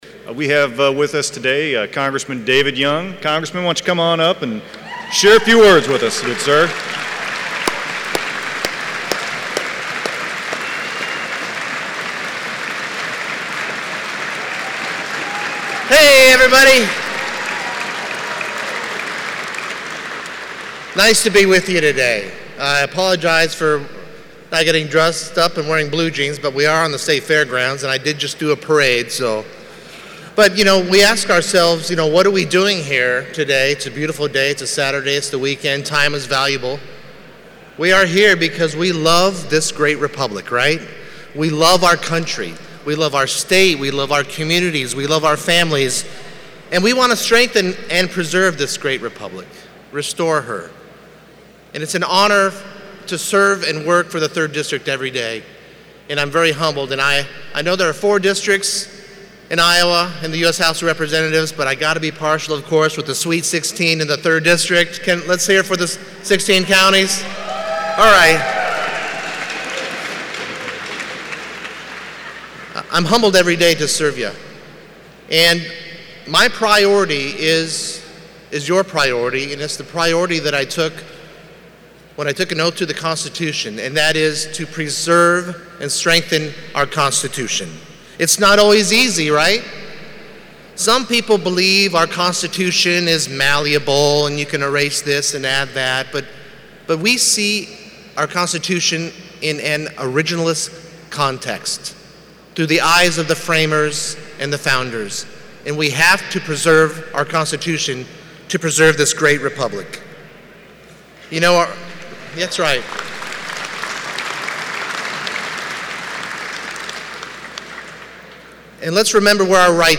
“Getting out of the Iran agreement, sitting down at the table with the leader of North Korea, moving our embassy from Tel Aviv to Jerusalem in Israel — some incredible things are happening right now,” Young said during a speech at the Iowa GOP’s state convention on Saturday.
AUDIO of Young’s speech, 6:00